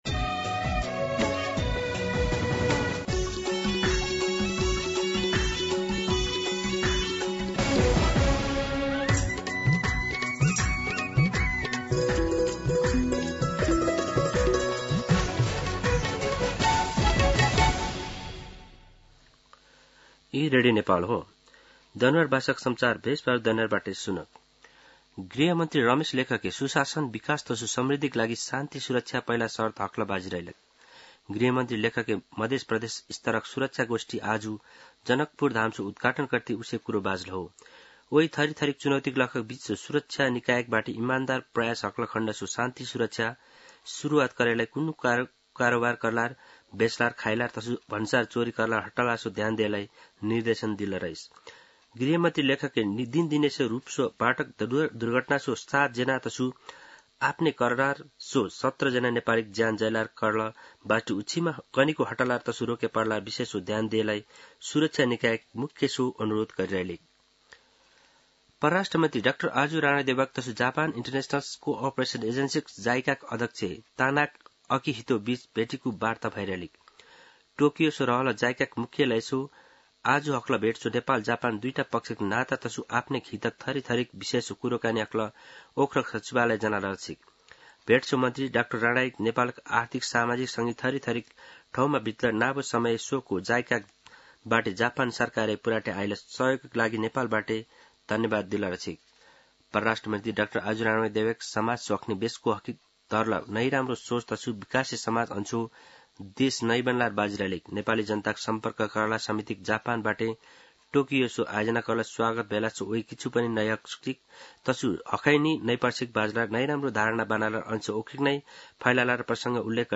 दनुवार भाषामा समाचार : ९ जेठ , २०८२